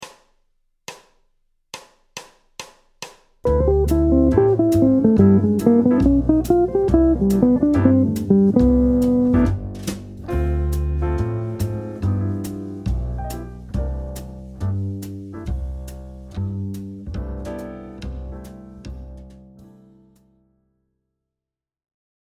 • 2 mesures de décompte
• 4 mesures de solo
• 4 mesures pour l’orchestre où vous pouvez rejouer le solo
• jusqu’au fondu pour finir
Phrase sur 4 mesures d’un ‘ii . V7. I’ en C Majeur.
Sur la sous-dominante (accord Dm7) les arpèges descendants de C ∆ et Am7 sont joués pour faire entendre les extensions à l’accord.
Sur la dominante (accord de G7 alt) le parti pris est de remonter la gamme de G altéré (Septième degré de Ab mineur mélodique)